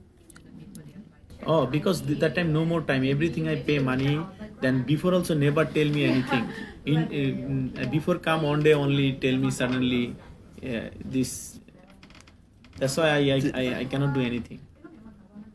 Do you hear from the audio clip how his tone of voice is subtly changing?